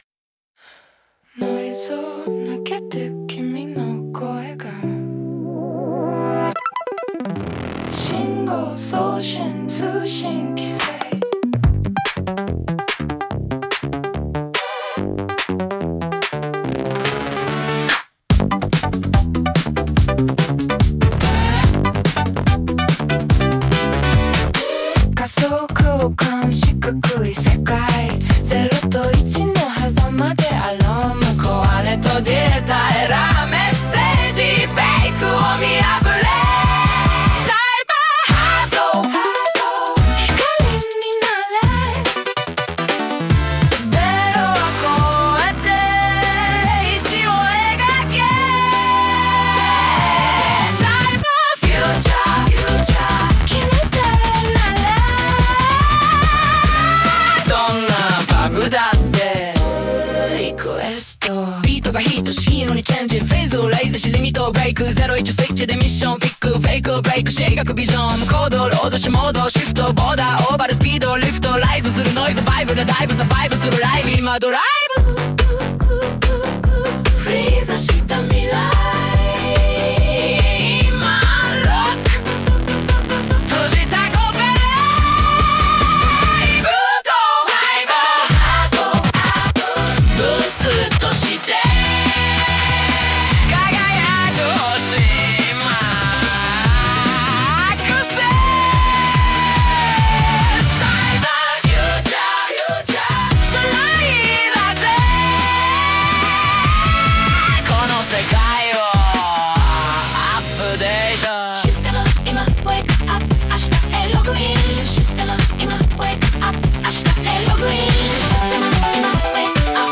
女性ボーカル